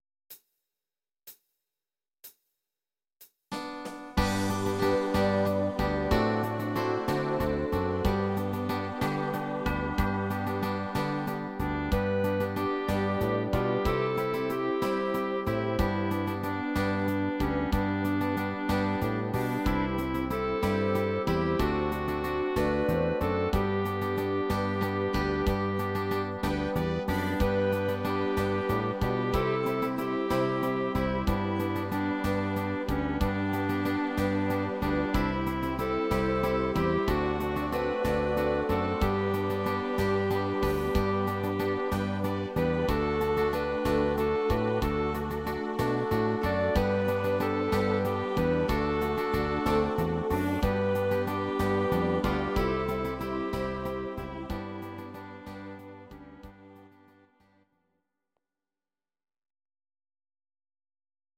6/8 takt